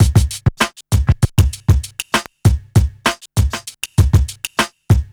1TI98BEAT1-R.wav